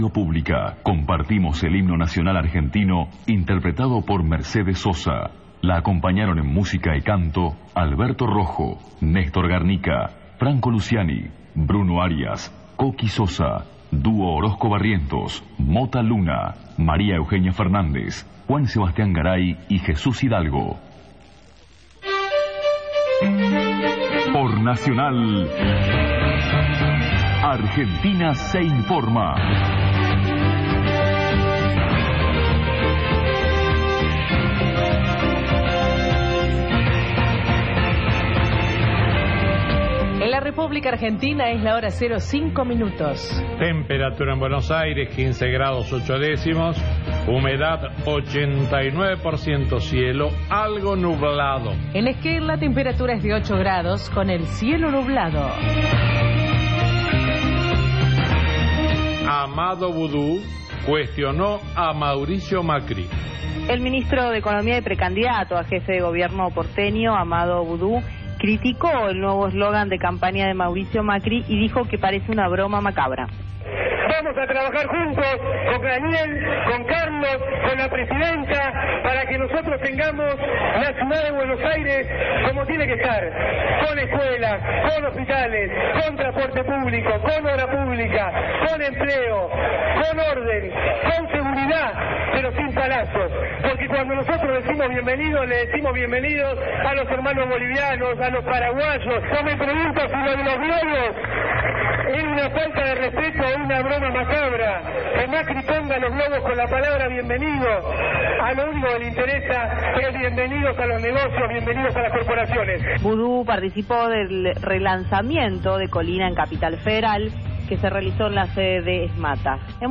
Programa desde el Multiteatro Dolina